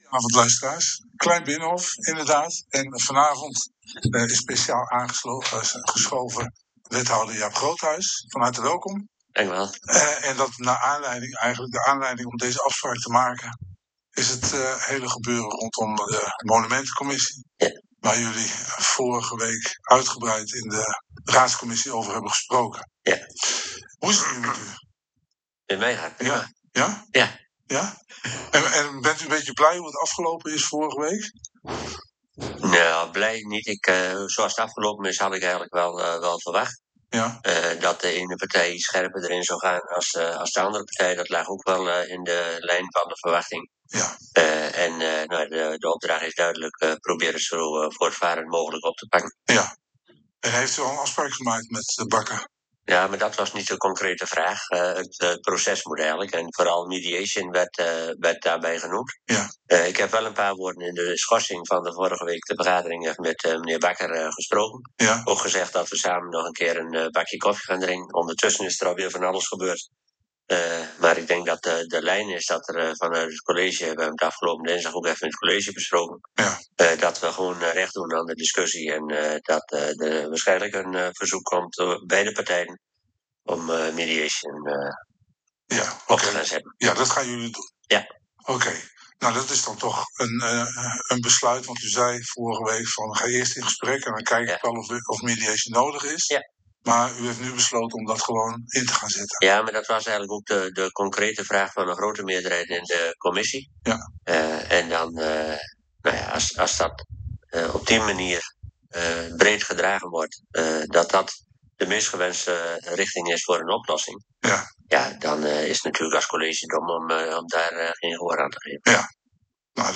‘Dit interview onthult een casestudy in hoe je burgerparticipatie in lokaal bestuur niet moet behandelen.